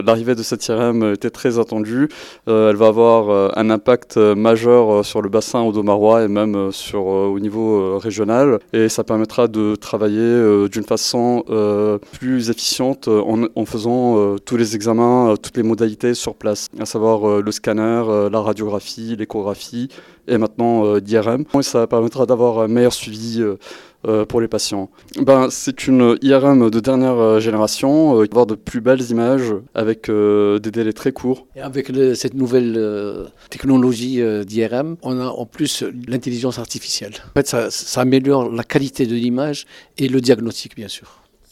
Radiologues :